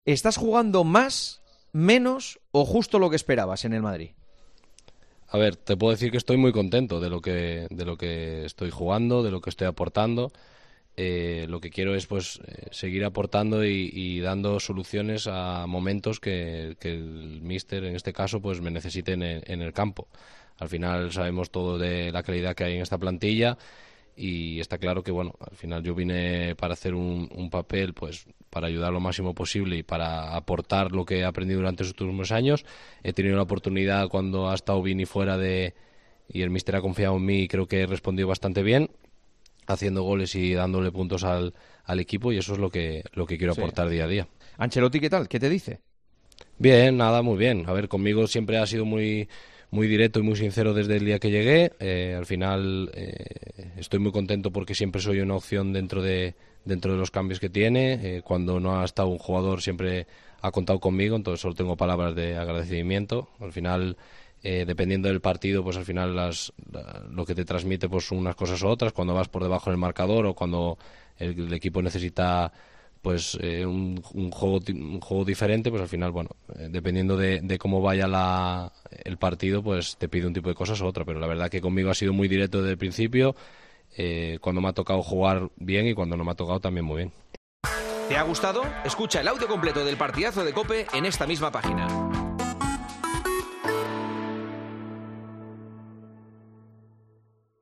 Por ahora, está respondiendo cuando Carlo Ancelotti recurre a él y sobre eso habló con Juanma Castaño en El Partidazo de COPE.